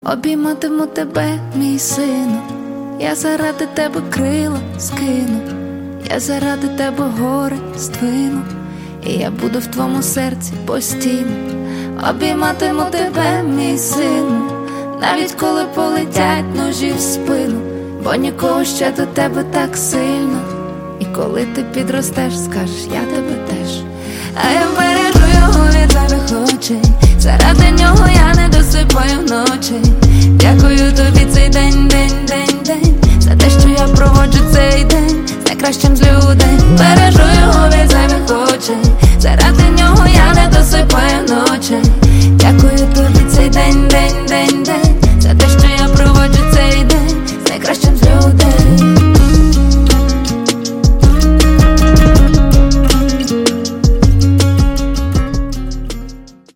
душевные
pop-rap
R&B